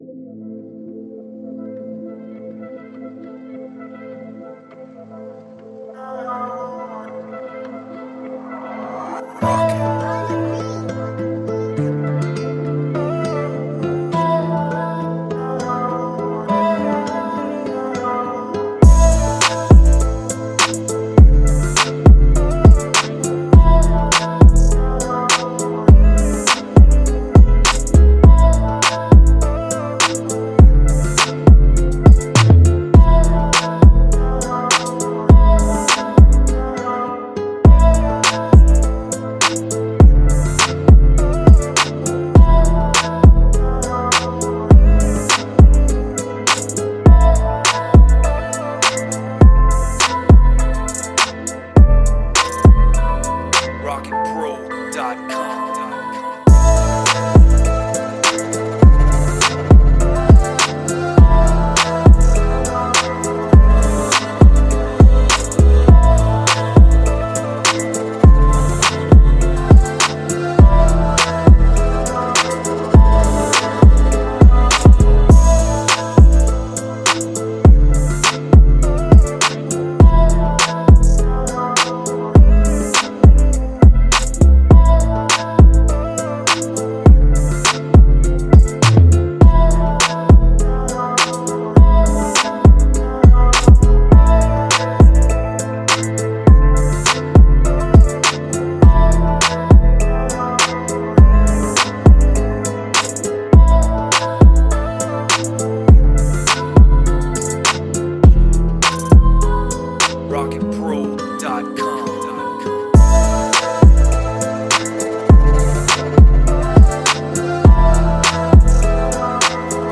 Pop beat with soft pads, guitars, and slide bass.